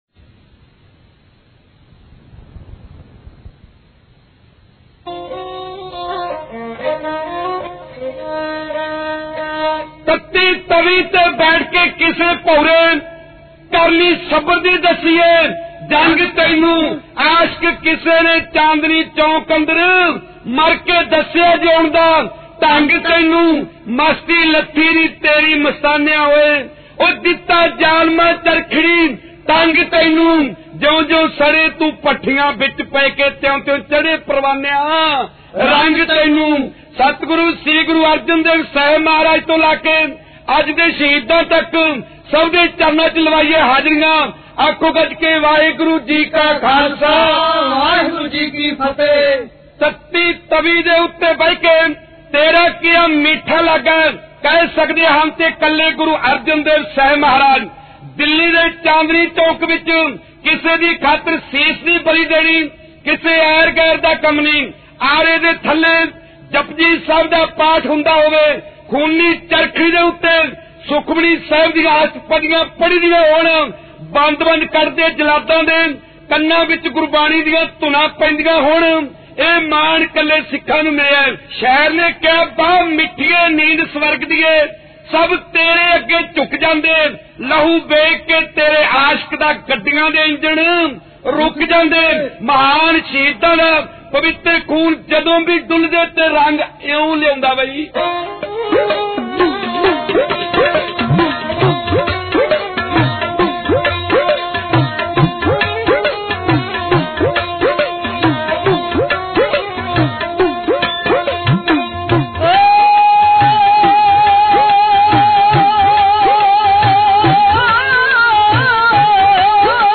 Genre: Dhadi Varan